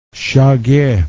shage as in Shoe Fingernails